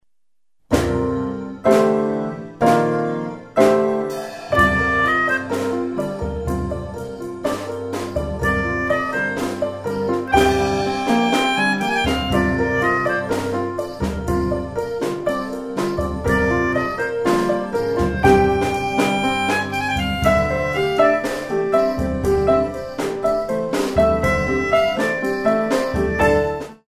4/4  mm=120